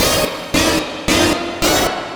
Horn Line Break 111-D#.wav